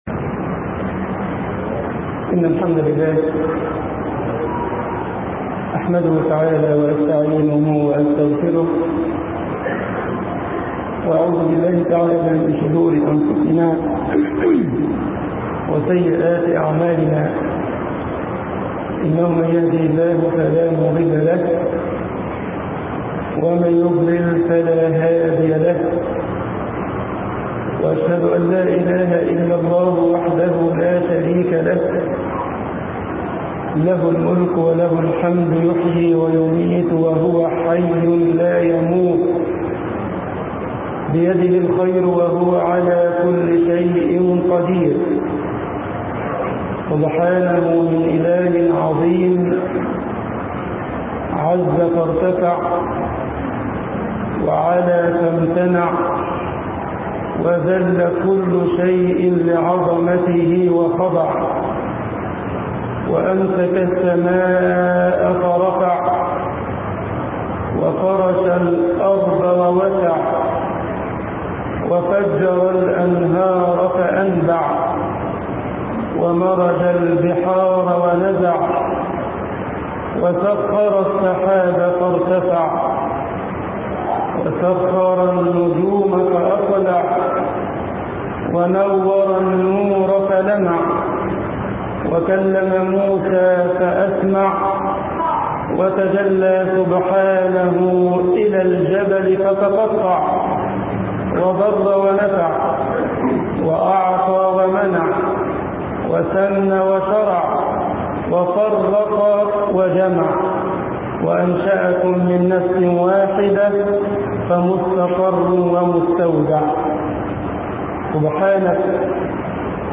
خطبة الجمعة
مسجد عمر بن الخطاب
Konn fi donia kannahla_Masjed omar ibn al khattab.mp3